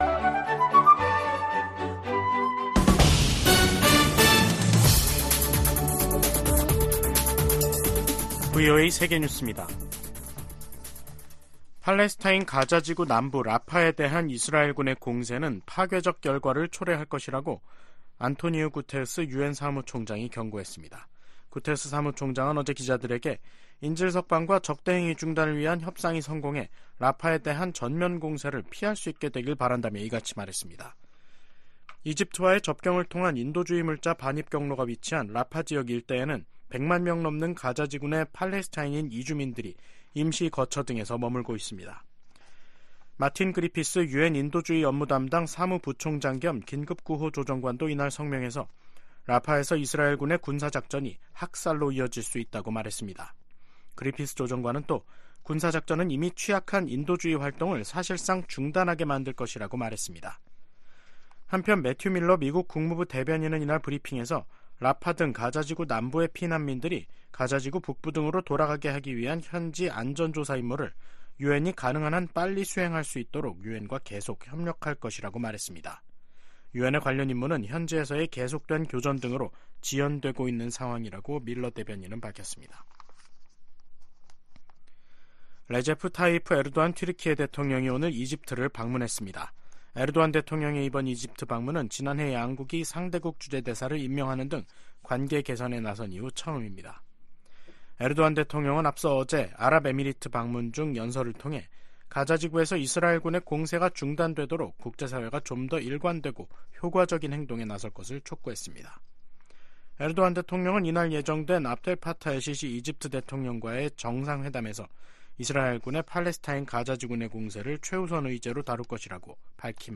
VOA 한국어 간판 뉴스 프로그램 '뉴스 투데이', 2024년 2월 14일 3부 방송입니다. 북한이 또 동해상으로 순항미사일을 여러 발 발사했습니다. 미 국무부는 북한이 정치적 결단만 있으면 언제든 7차 핵실험을 감행할 가능성이 있는 것으로 판단하고 있다고 밝혔습니다. 백악관이 북한의 지속적인 첨단 무기 개발 노력의 심각성을 지적하며 동맹 관계의 중요성을 강조했습니다.